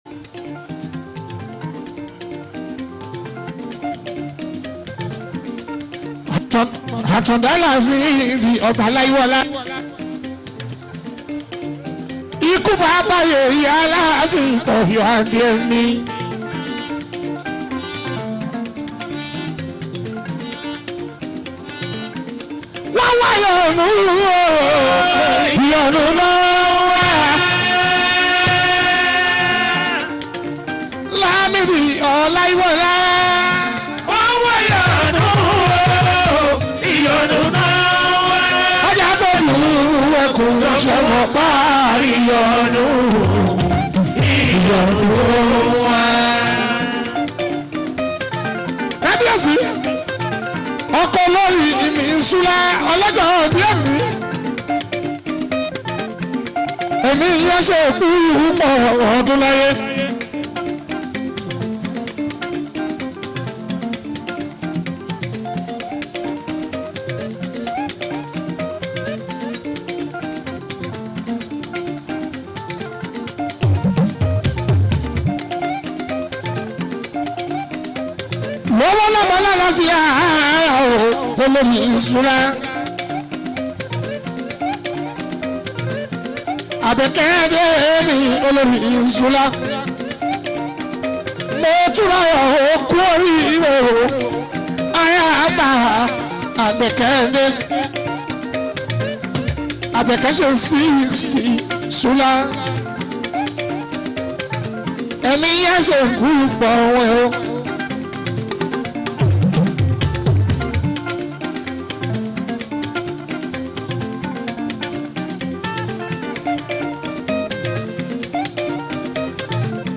Nigerian Yoruba Fuji track
especially if you’re a lover of Yoruba Fuji Sounds